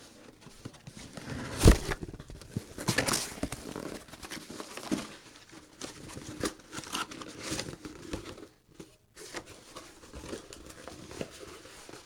cardboard box